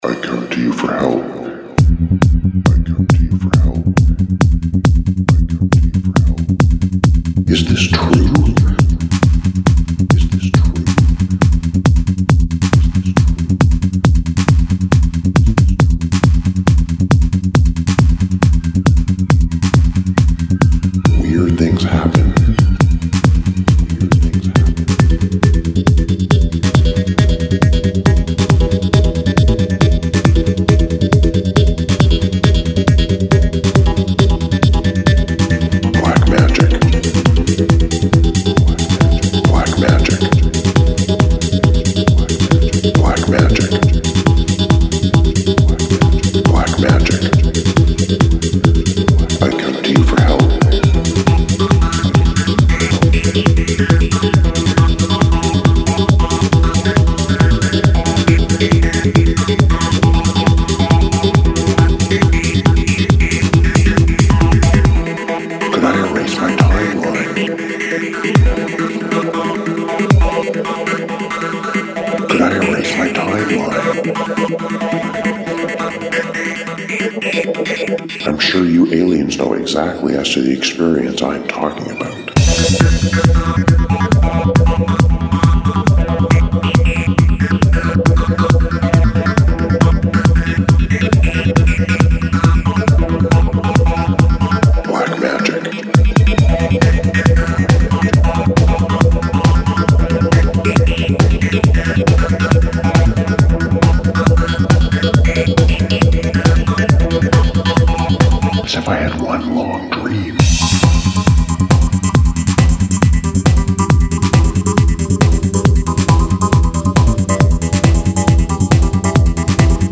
Psytrance-ish..... I think?